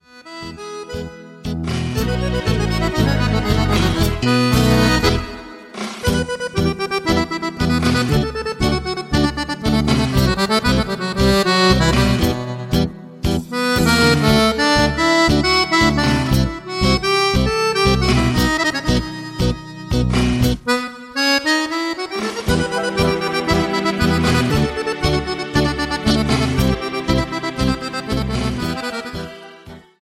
TANGO  (2.47)